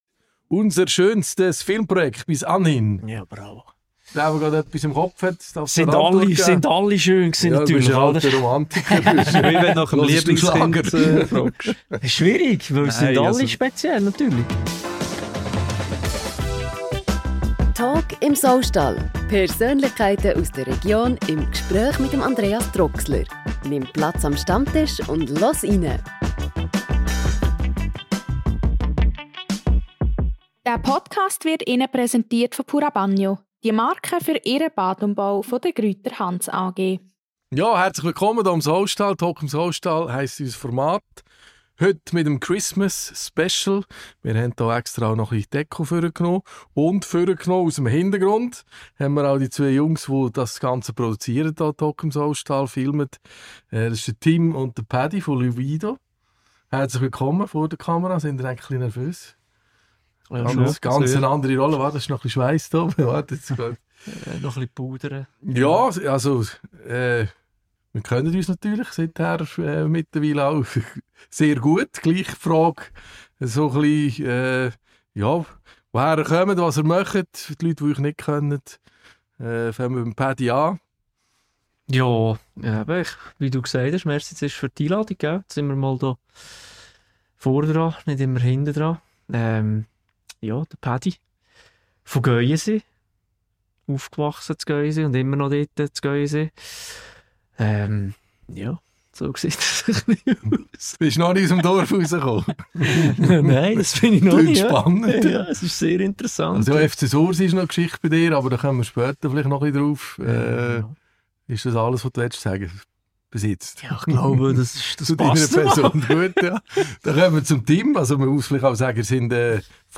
Beschreibung vor 3 Monaten Im monatlichen Podcast im ehemaligen Saustall in Nottwil erfährst du mehr über die Menschen aus der Region.